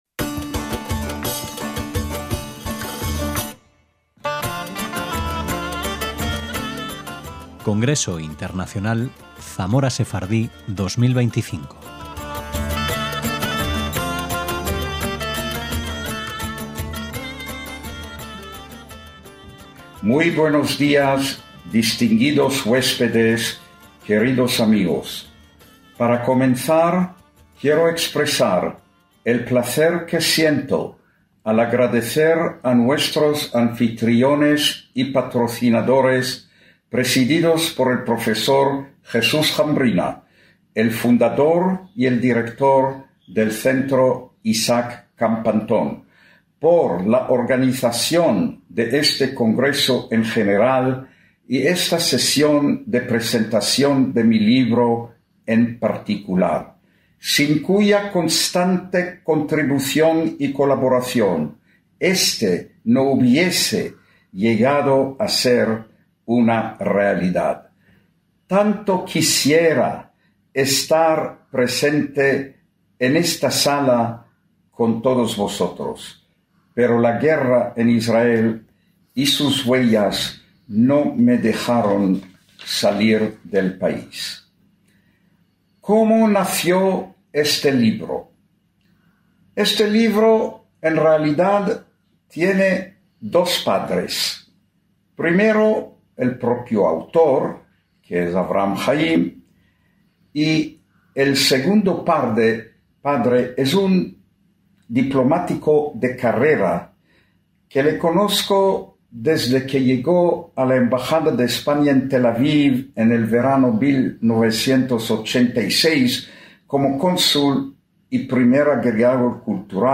CONGRESO INTERNACIONAL ZAMORA SEFARDÍ 2025
ponencia